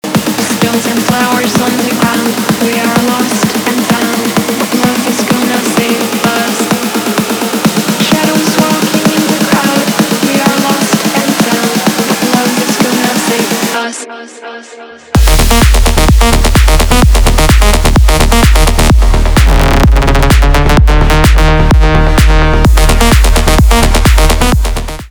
техно
битовые , басы , крутые , качающие , танцевальные